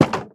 Minecraft Version Minecraft Version latest Latest Release | Latest Snapshot latest / assets / minecraft / sounds / block / fence_gate / open1.ogg Compare With Compare With Latest Release | Latest Snapshot
open1.ogg